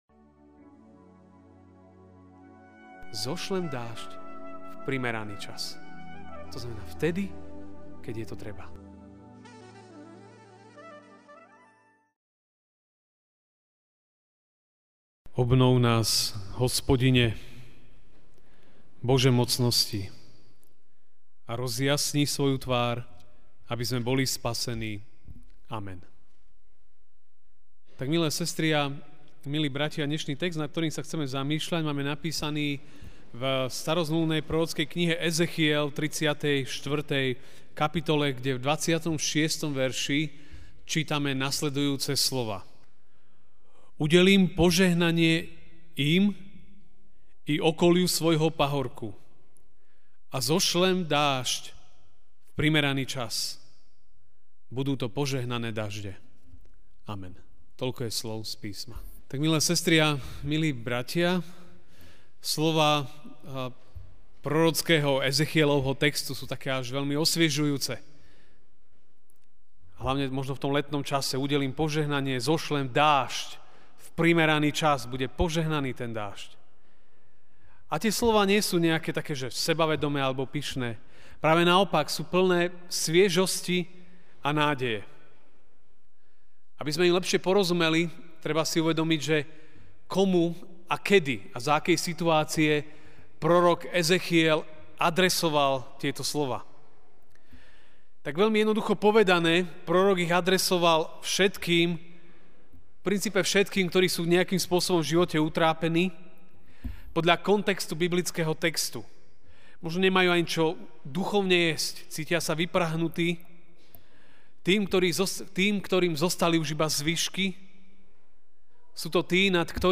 aug 27, 2017 Požehnaný dážď MP3 SUBSCRIBE on iTunes(Podcast) Notes Sermons in this Series Ranná kázeň: Požehnaný dážď (Ezechiel 34:26) Udelím požehnanie im i okoliu svojho pahorka a zošlem dážď v primeraný čas.
kazen-27.8.-mp3-1.mp3